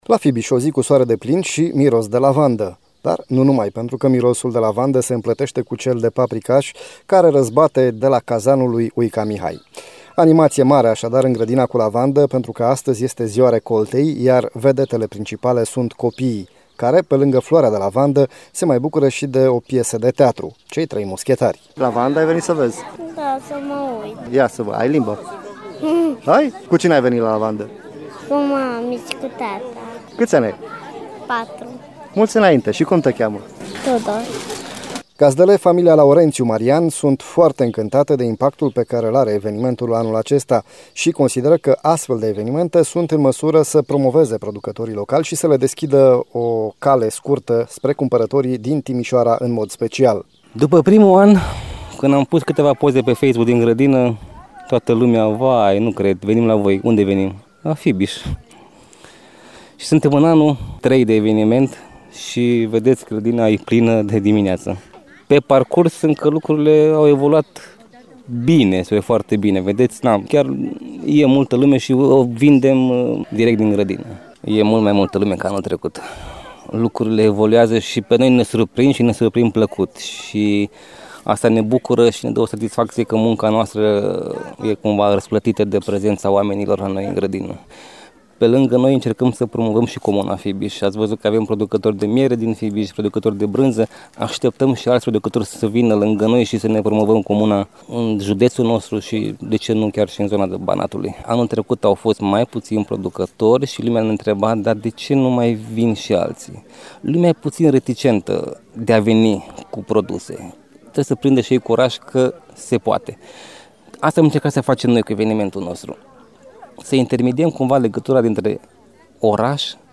Animație mare în grădina cu lavandă pentru că astăzi este Ziua recoltei, iar vedetele principale sunt copii care, pe lângă floarea de lavandă, se bucură și de o piesă de teatru – Cei trei mușchetari.